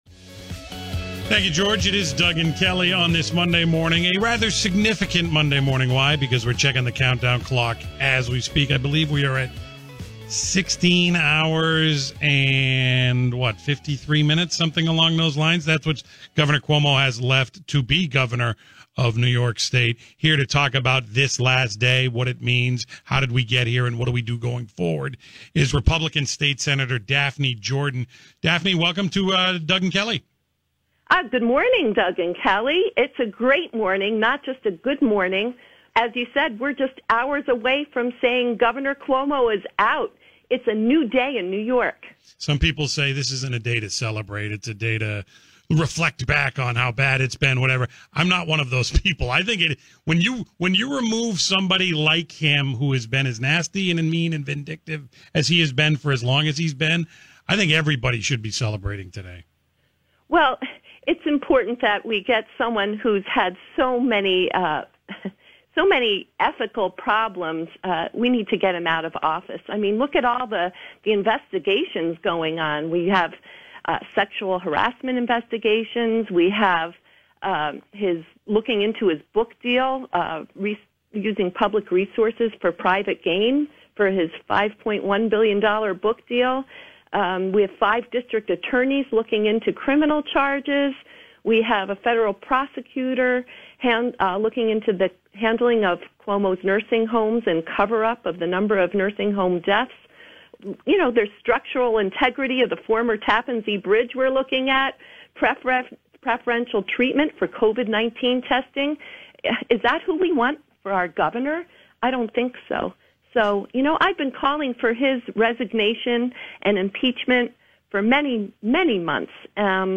Senator Jordan's radio interview